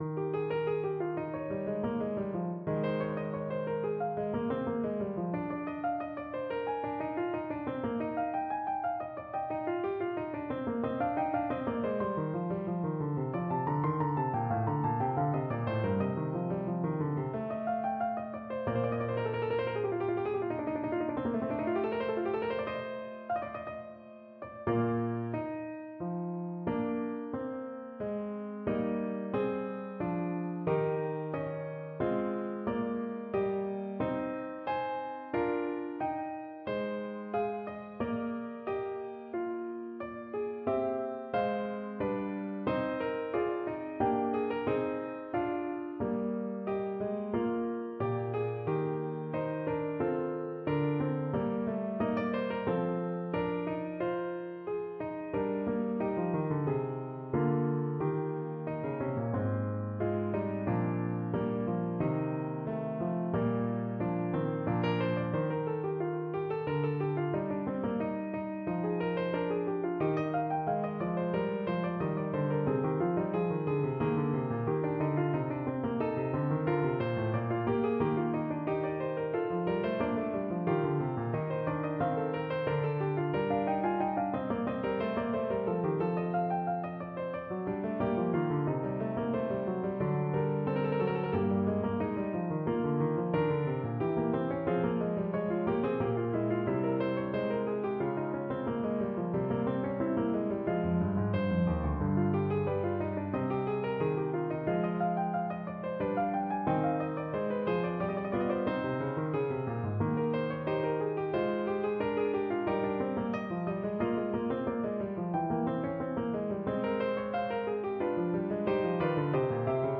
Piano version
No parts available for this pieces as it is for solo piano.
4/4 (View more 4/4 Music)
Piano  (View more Advanced Piano Music)
Classical (View more Classical Piano Music)